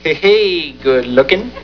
Male Voice -